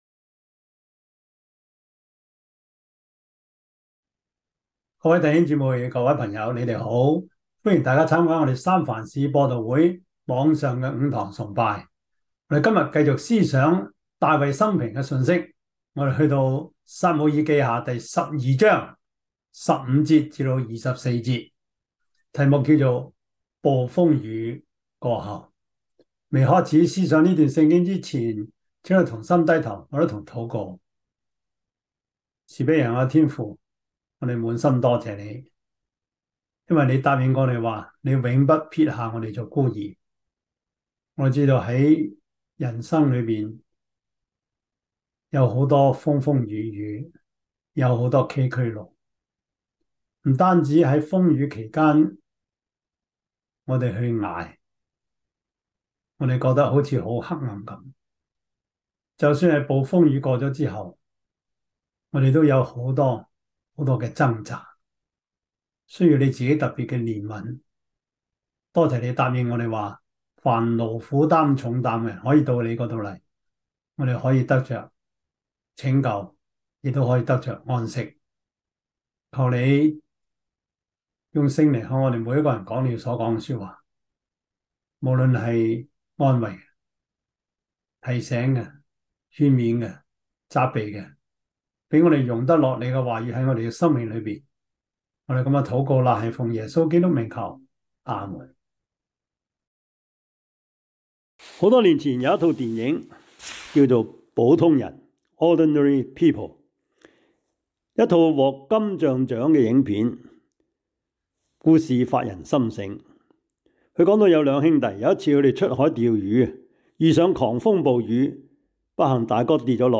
撒母耳記下 12:15-25 Service Type: 主日崇拜 撒母耳記下 12:15-25 Chinese Union Version
Topics: 主日證道 « 誰肯為主效命?